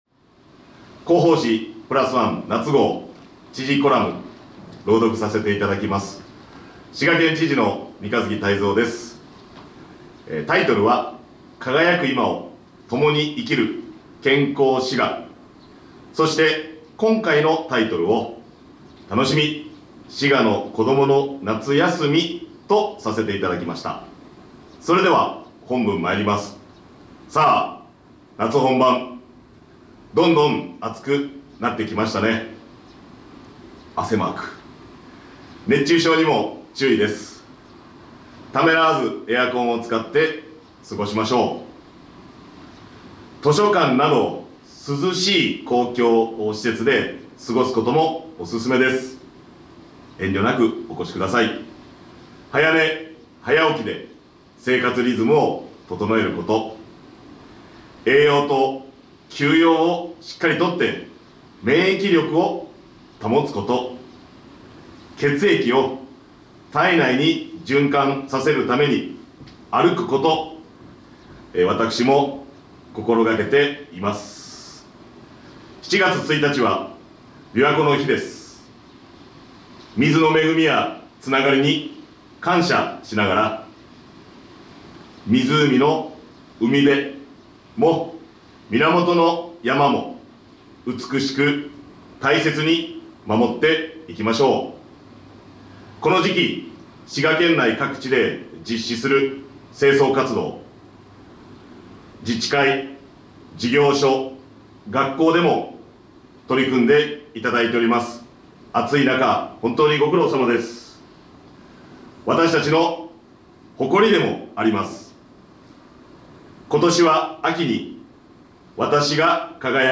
vol.52 P15 (PDF:2 MB) 【音声版】みんなでプラスワン（知事朗読）Vol.52 (mp3:6 MB) ほっとサロン 「滋賀プラスワン」夏号へのご感想や県政へのご意見などをお寄せいただいた方の中から抽選でプレゼントが当たります！